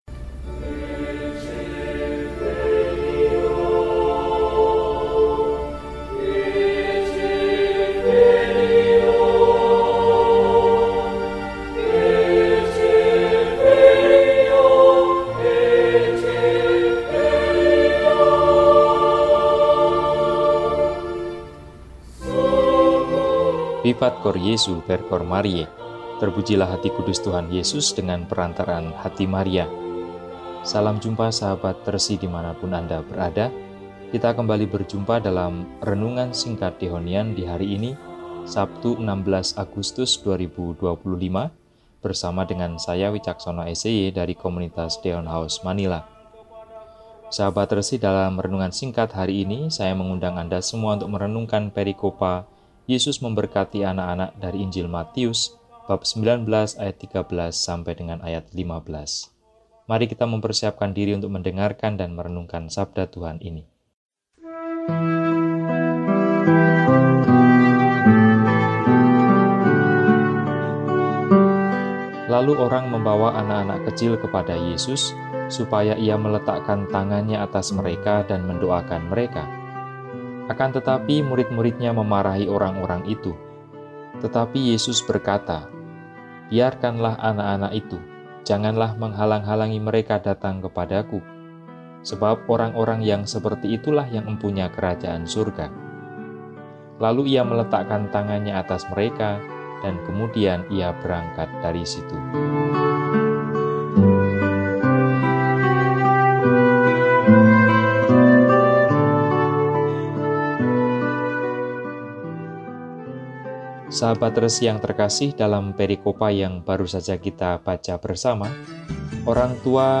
Sabtu, 16 Agustus 2025 – Hari Biasa Pekan XIX – RESI (Renungan Singkat) DEHONIAN